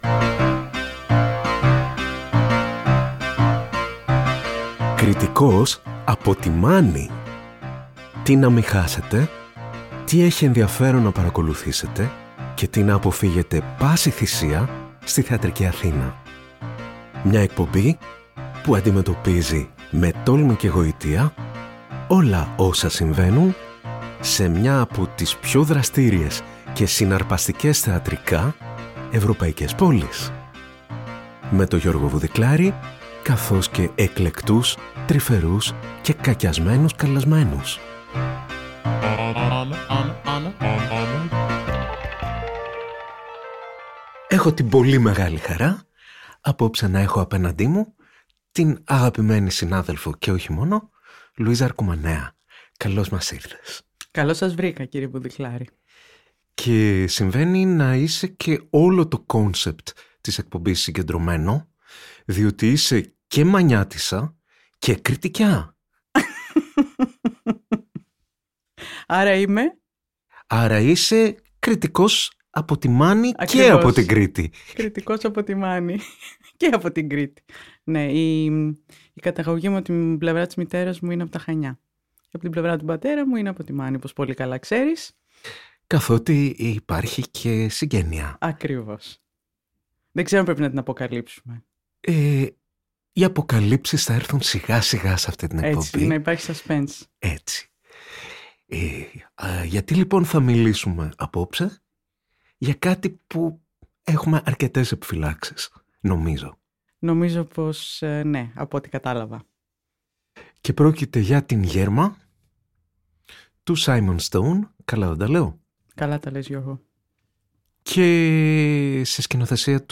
Συζητήσεις μεταξύ κριτικών που συμφωνούν ή διαφωνούν για το τι δεν πρέπει να χάσουμε, αλλά και το τι πρέπει να αποφύγουμε στη θεατρική Αθήνα.